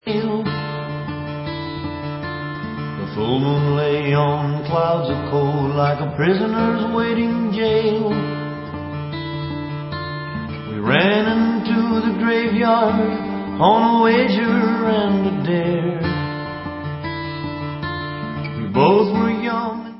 Counrty swing with rockabilly flavors